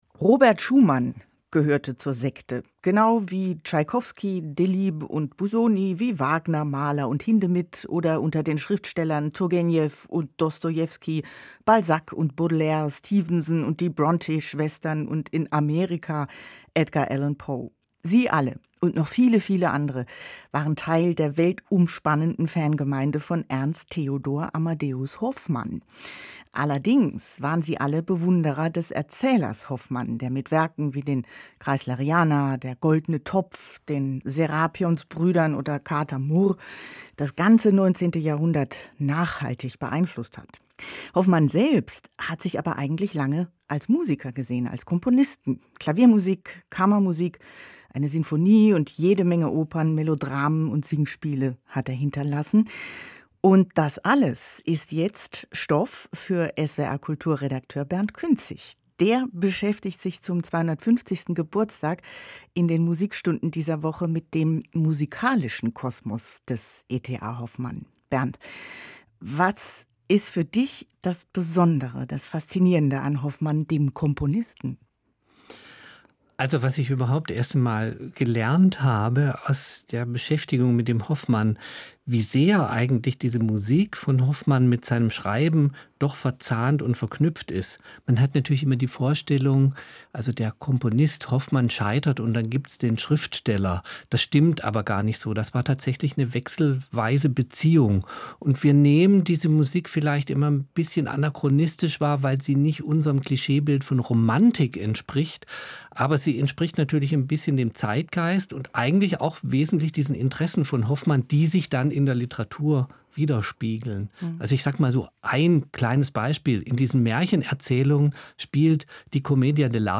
Musikgespräch
Gespräch mit